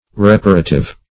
Reparative \Re*par"a*tive\ (r?-p?r"?-t?v), a.